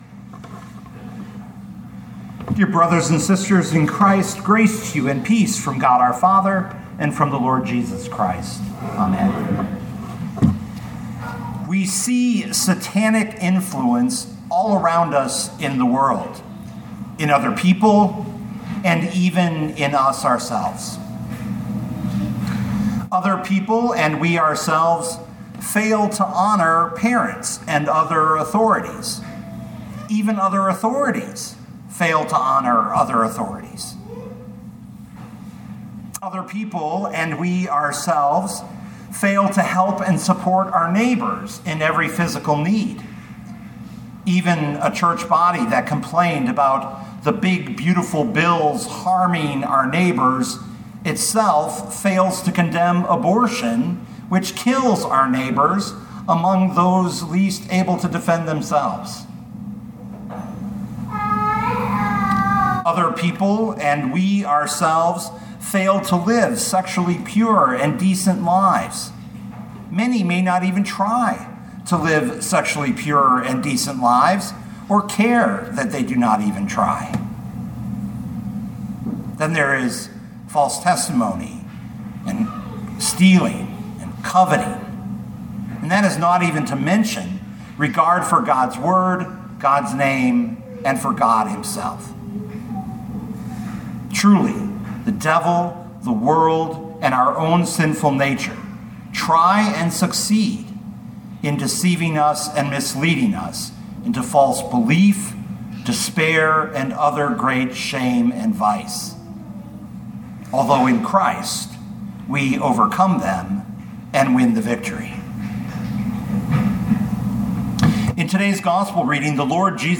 2025 Luke 10:1-20 Listen to the sermon with the player below, or, download the audio.